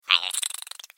دانلود صدای حشره 5 از ساعد نیوز با لینک مستقیم و کیفیت بالا
جلوه های صوتی